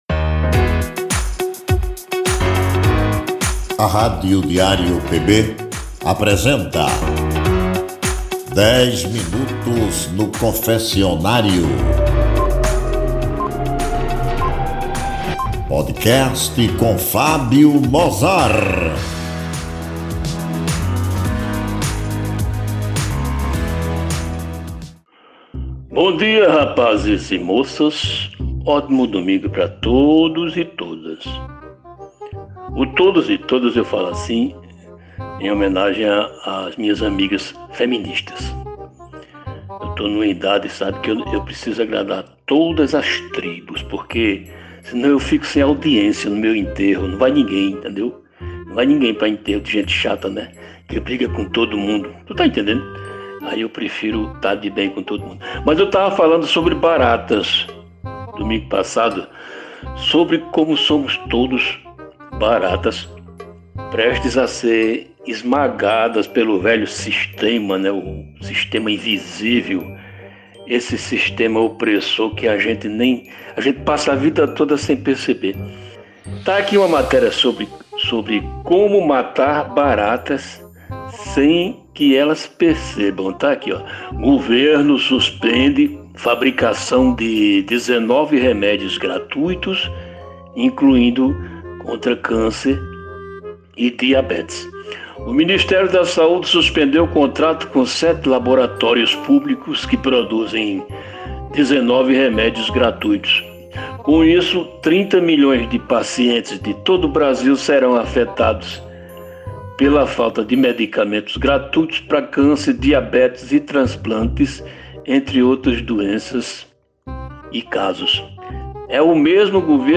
é um programa com um papo descontraído, às vezes incomum, sobre as trivialidades do nosso cotidiano.